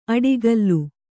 ♪ aḍigallu